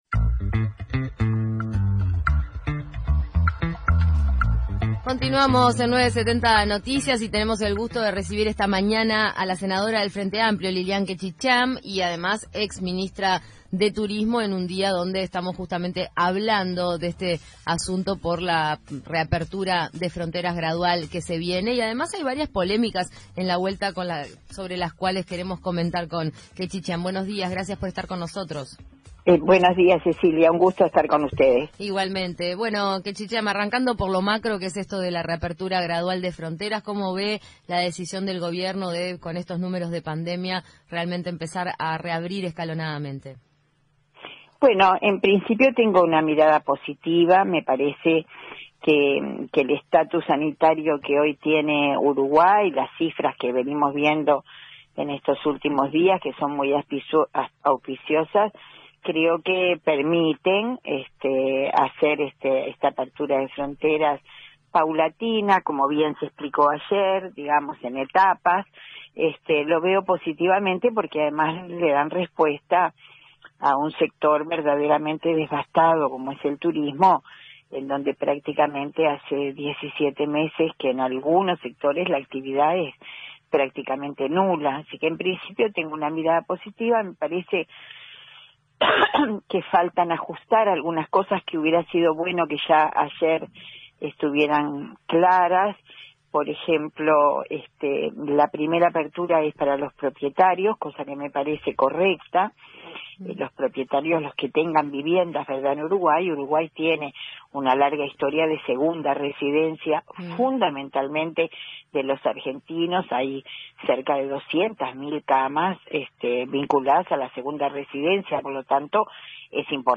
Después de días agitados por sus cruces con el ministro de Turismo, Germán Cardoso, y el intendente de Maldonado, Enrique Antía, la senadora frenteamplista y exministra de Turismo, Liliam Kechichián, opinó de las críticas a su política frente a la cartera y la decisión del gobierno de abrir las fronteras, en entrevista con 970 Noticias Primera Edición.